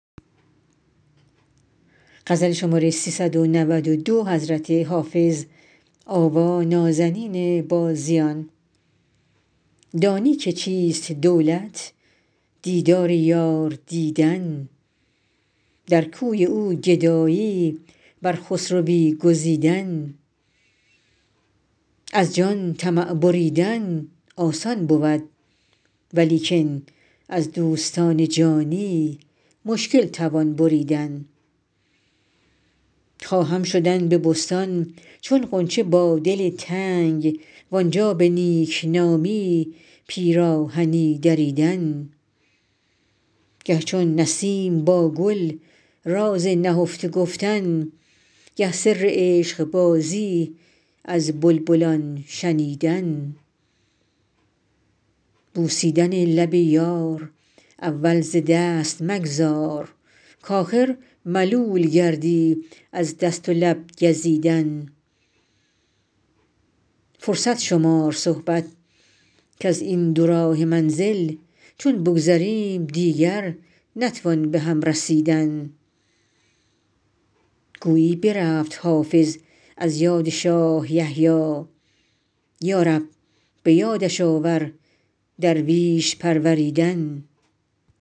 حافظ غزلیات غزل شمارهٔ ۳۹۲ به خوانش